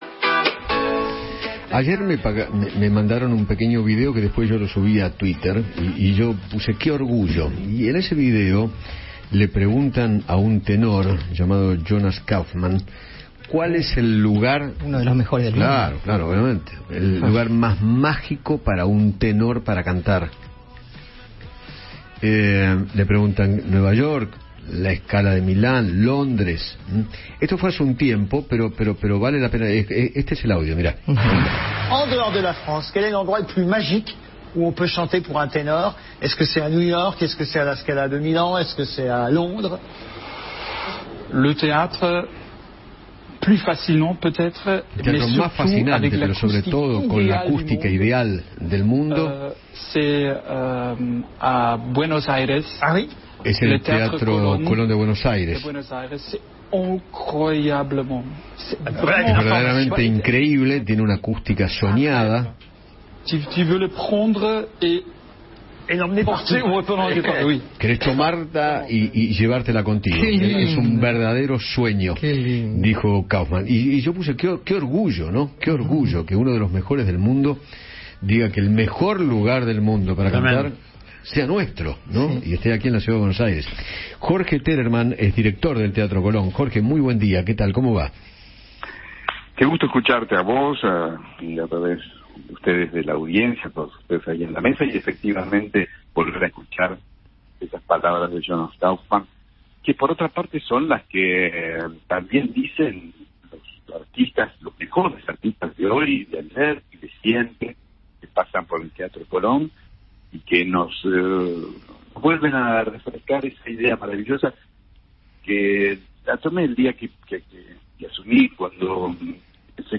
Jorge Telerman, director del Teatro Colón, habló con Eduardo Feinmann acerca de los dichos del tenor alemán, Jonas Kaufmann, sobre del histórico edificio argentino.